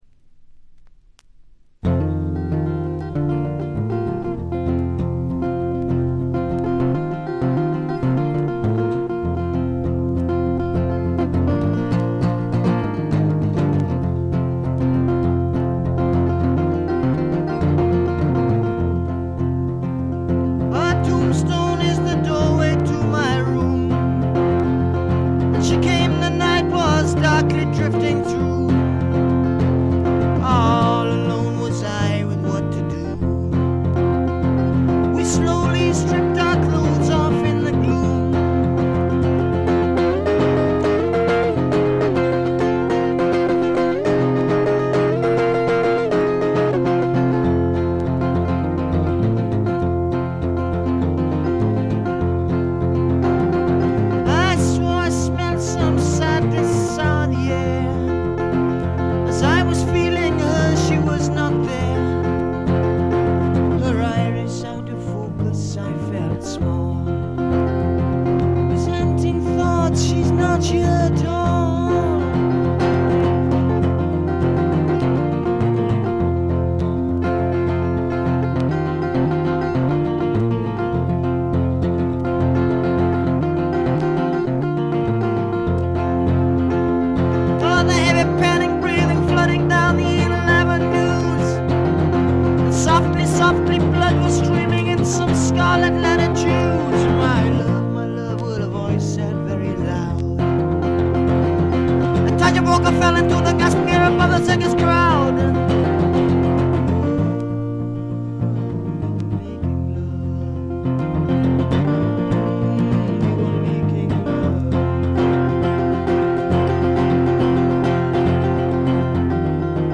わずかなノイズ感のみ。
内容的にはおそろしく生々しいむき出しの歌が聞こえてきて、アシッド・フォーク指数が異常に高いです。
試聴曲は現品からの取り込み音源です。
Vocals, Acoustic Guitar